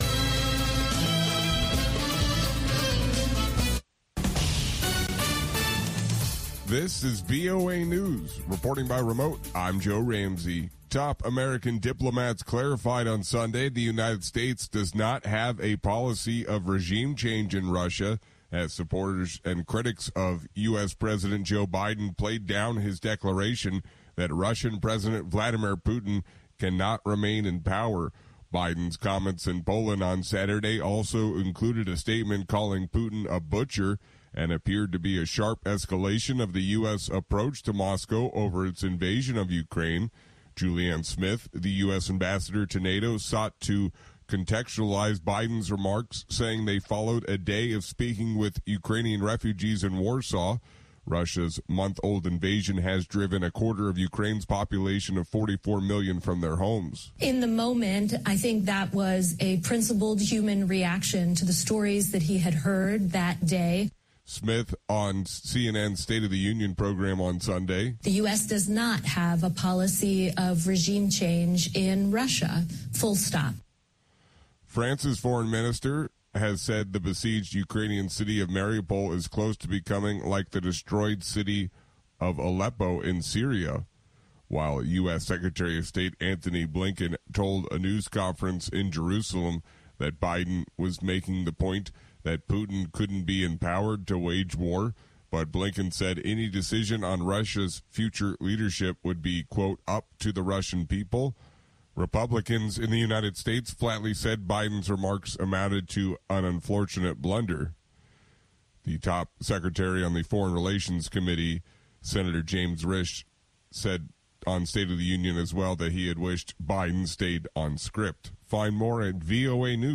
هەواڵەکانی 3 ی پاش نیوەڕۆ
هەواڵە جیهانیـیەکان لە دەنگی ئەمەریکا